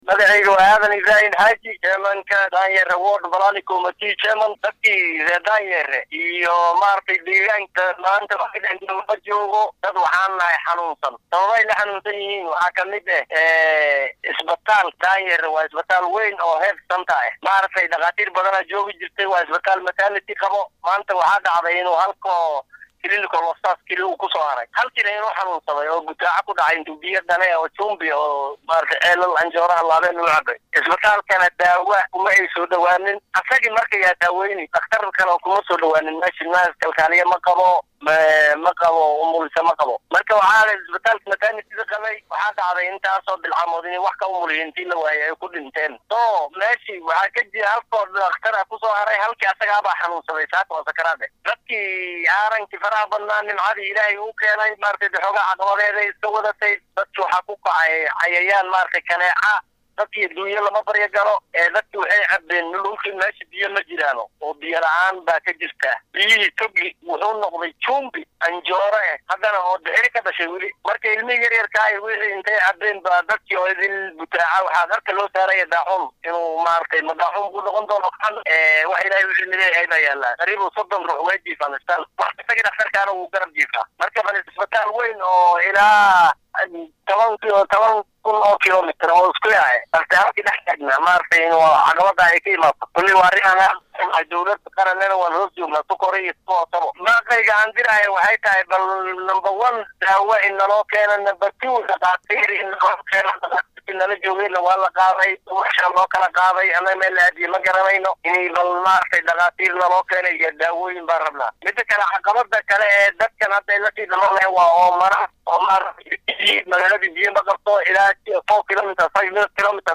Shacabka ku nool Degmada Daanyeere ee deegaan baarlamaaneedka Balambala ee ismaamulka Garissa ayaa ka cabanaya dhibaatooyin dhanka caafimaadka, biyaha iyo waddooyinka. Qaar ka mid ah dadka ku nool Daanyeere oo la hadlay warbaahinta Star ayaa sheegay in ay walaac ka qabaan in uu saameeyo cudurka daacuunka.